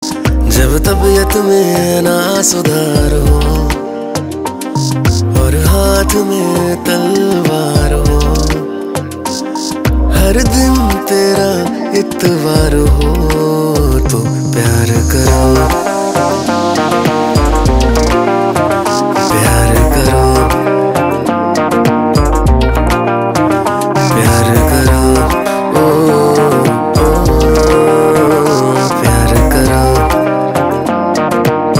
• Simple and Lofi sound
• High-quality audio
• Crisp and clear sound